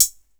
T6 Hats15.wav